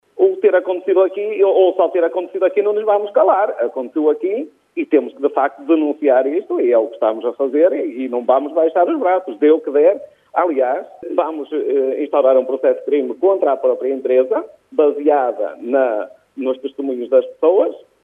António Pereira, presidente da Junta da União de Freguesias de Quintiães e Aguiar, diz que não vão baixar os braços.